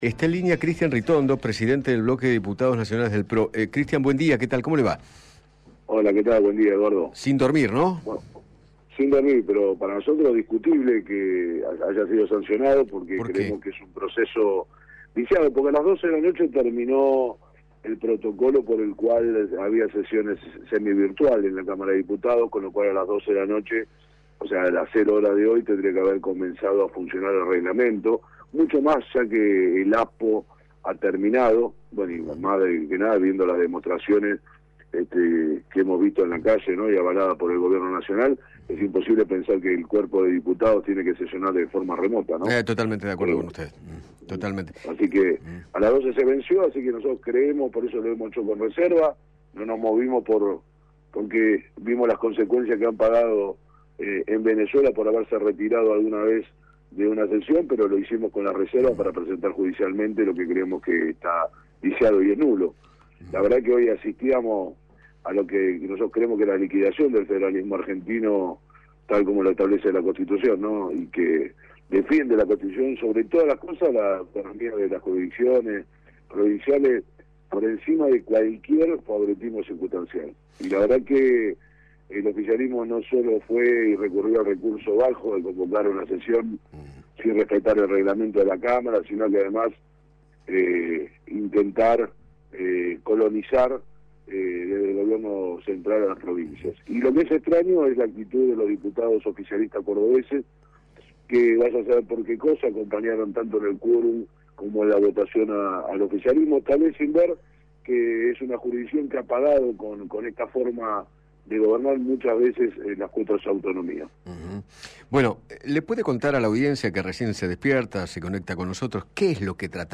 Cristian Ritondo, Diputado Nacional por la Provincia de Buenos Aires y Presidente del bloque PRO, dialogó con Eduardo Feinmann sobre el proyecto que aprobó esta mañana la Cámara de Diputados, el cual, recorta los fondos de la coparticipación a la Ciudad.